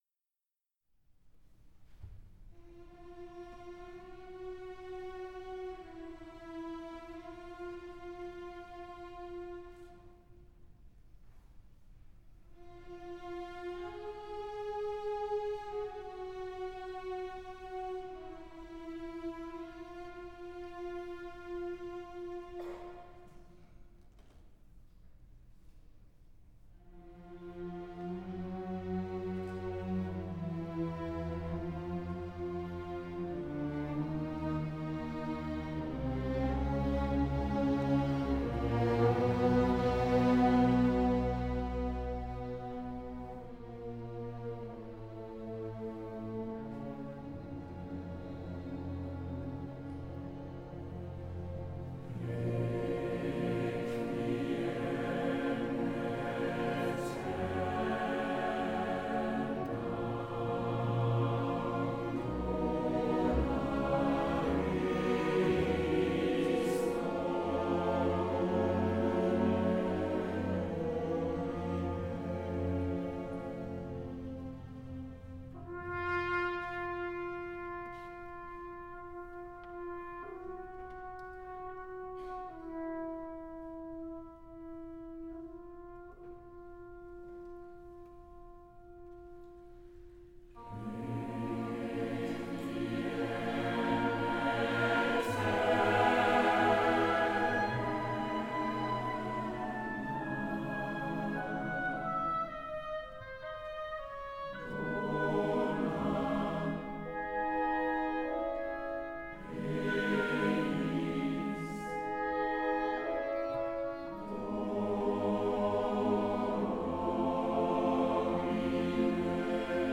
Konzert live, Mai 2017 (Stadtcasino Bern)